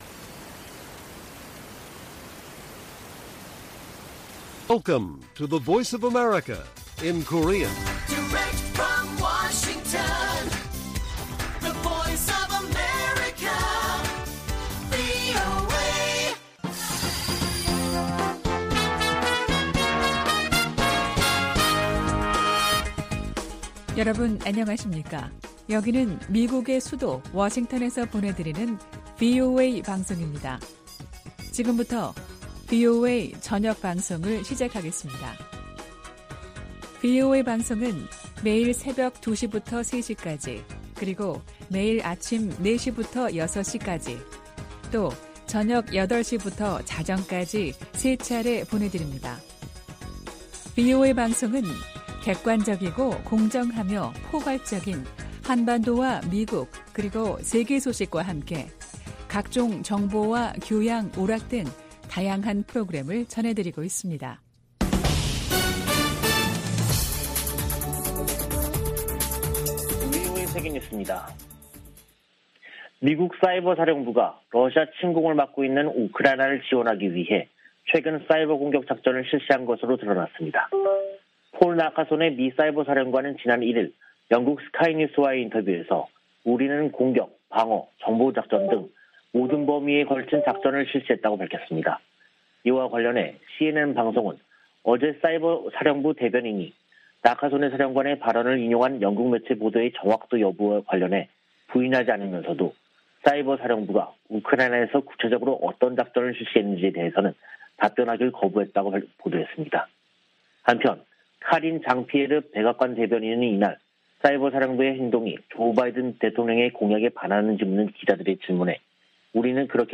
VOA 한국어 간판 뉴스 프로그램 '뉴스 투데이', 2022년 6월 3일 1부 방송입니다. 미국과 한국, 일본의 북핵 수석대표들은 북한의 7차 핵실험 준비 동향에 대해 긴밀한 공조를 통한 억제력 강화 등을 경고했습니다. 미 국무부는 2021년도 종교자유보고서에서 북한의 종교 탄압이 심각히 우려되고 있다고 지적했습니다. 웬디 셔먼 미 국무부 부장관은 북한에 추가 도발을 자제하고 대화의 길로 나올 것을 거듭 촉구했습니다.